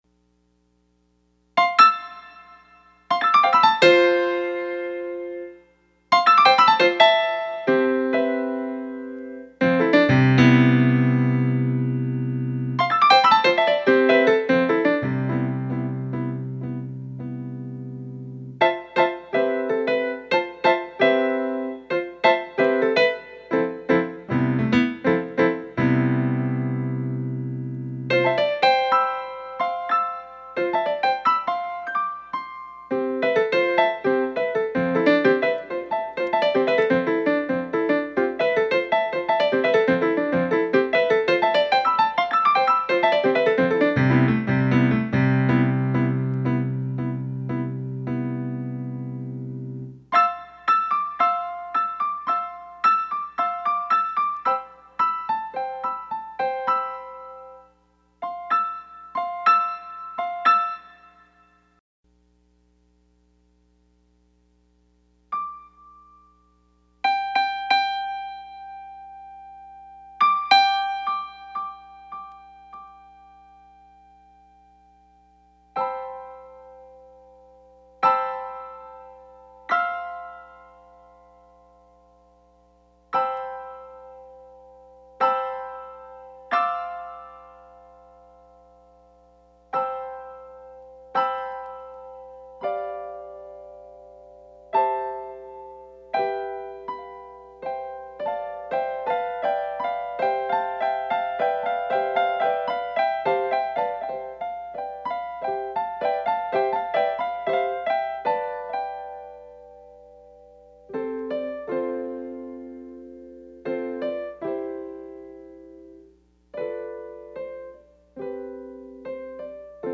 Des pratiques au piano pour illustrer comment se déploit une soirée avec des doigts qui retrouvent  le clavier après une absence.
Un thème à redécouvrir avec les variations qui s’y greffent pour en rejoindre un autre.